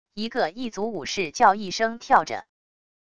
一个异族武士叫一声跳着wav音频